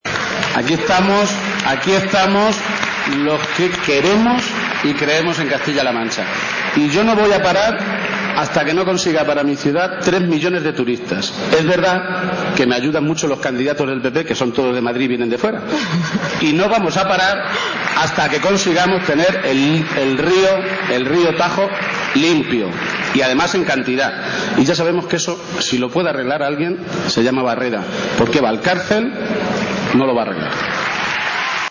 Alrededor de 6.000 personas han arropado hoy al presidente Barreda en el acto que los socialistas castellano-manchegos han celebrado en la localidad de Alcázar de san Juan (Ciudad Real).
El encuentro de los socialistas comenzó con las intervenciones de los secretarios generales del partido, que acompañados por las candidaturas autonómicas y de las principales localidades de la Región, respondieron a unas preguntas formuladas por una presentadora.